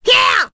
Lakrak-Vox_Attack2.wav